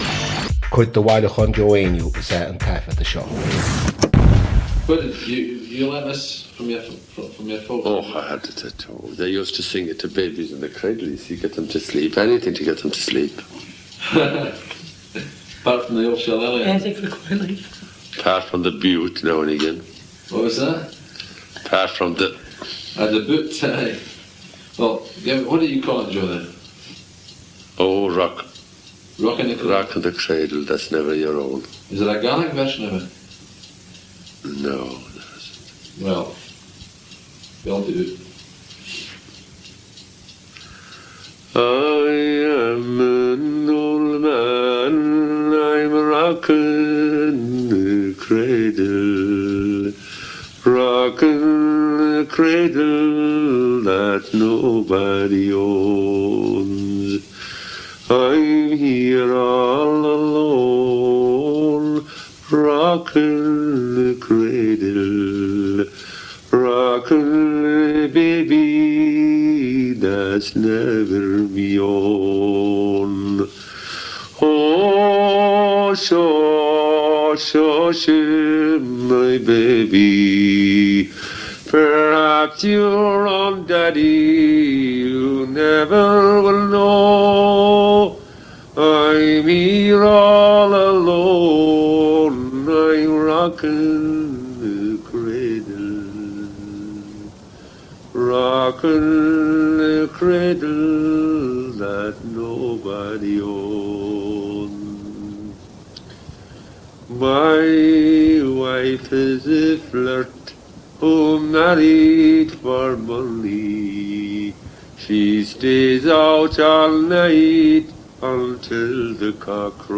• Catagóir (Category): song.
• Ainm an té a thug (Name of Informant): Joe Heaney.
• Suíomh an taifeadta (Recording Location): Clydebank, Scotland.
The air to this song is the same as that of another lullaby Joe recorded, ‘Seoithín Seo-hó.’